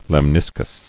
[lem·nis·cus]